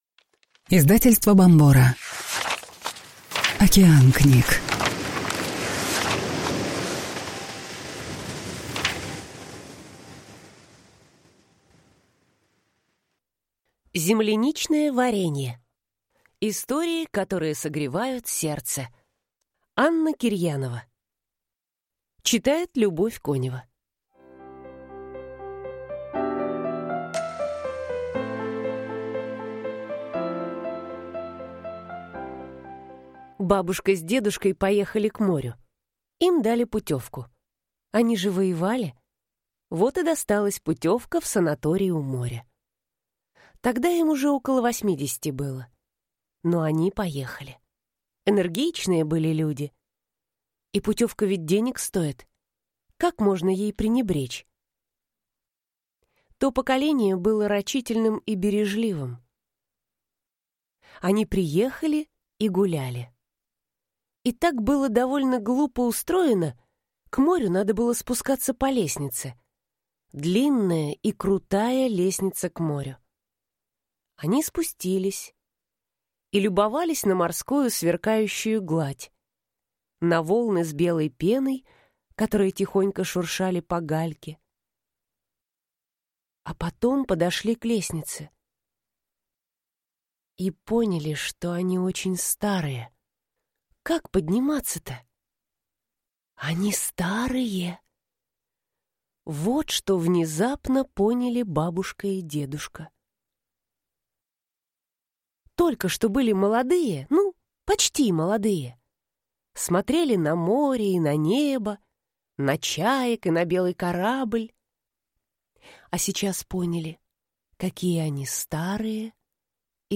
Аудиокнига Земляничное варенье. Истории, которые согревают сердце | Библиотека аудиокниг